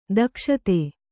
♪ dakṣate